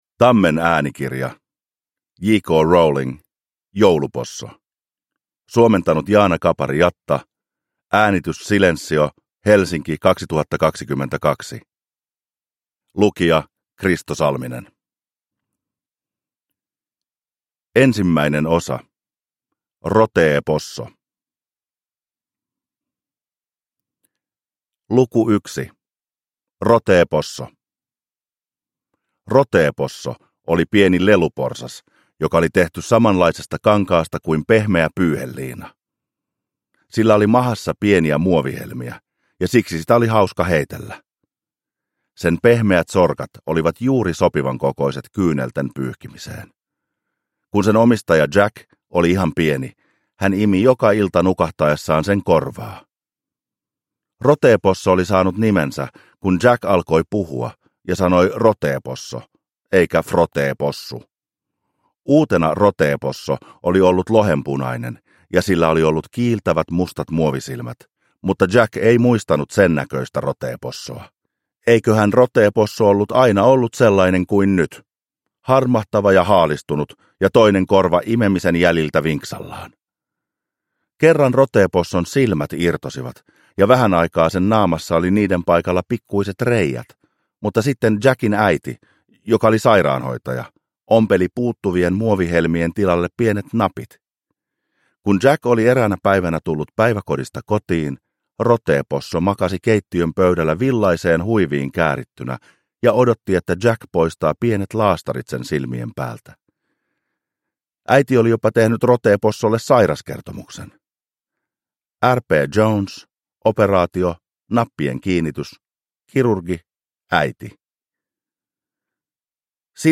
Jouluposso – Ljudbok – Laddas ner
Uppläsare: Kristo Salminen